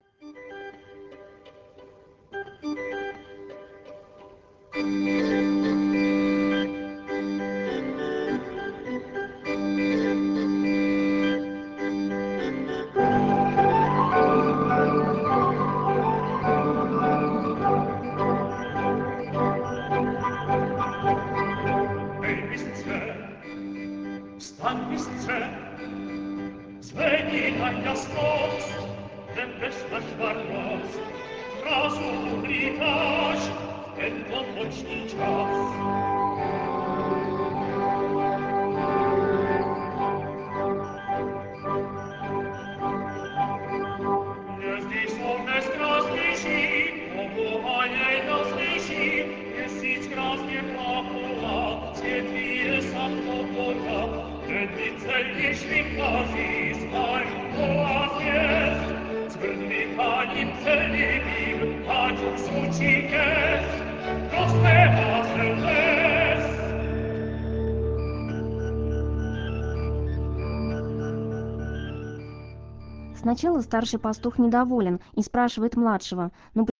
В первой части, «Кирии», композитор ярко продемонстрировал свой драматургический талант. После вступительного «диалога» органа и смычковых инструментов, своего рода «музыки сфер», следует разговор пастушка с пастухом.